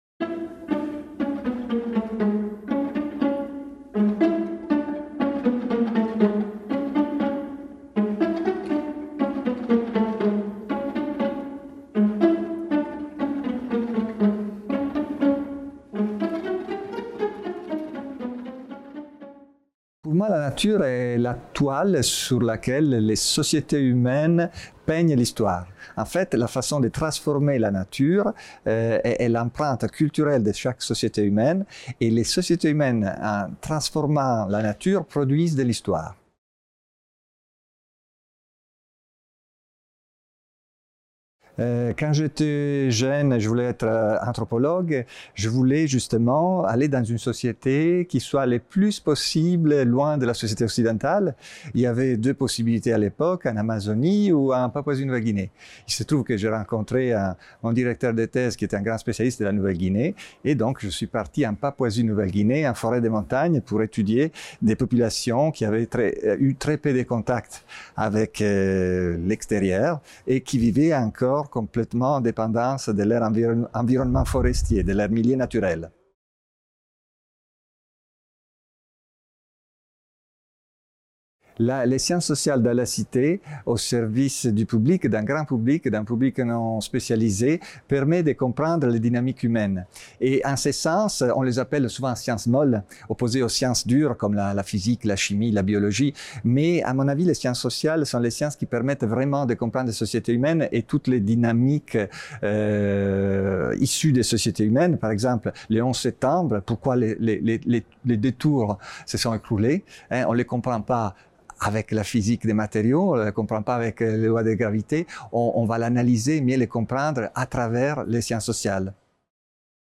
Favoris Ajouter à une playlist Entretien